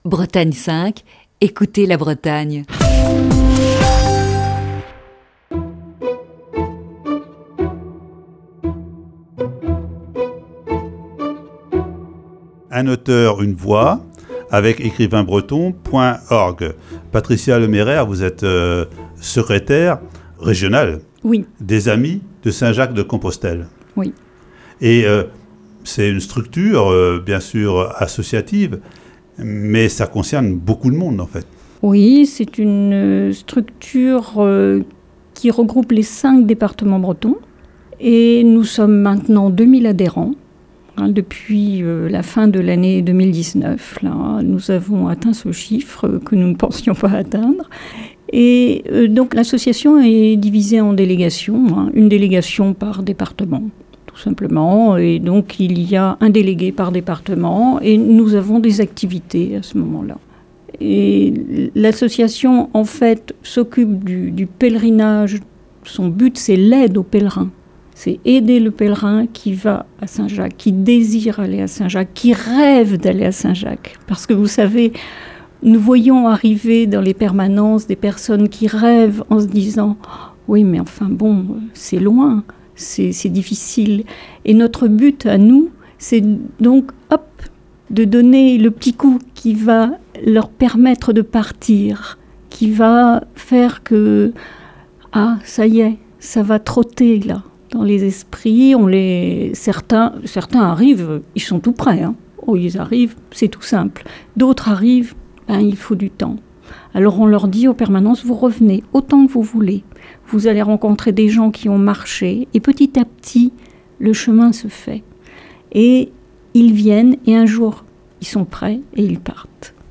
Aujourd'hui, troisième partie de cet entretien diffusé le 12 février 2020.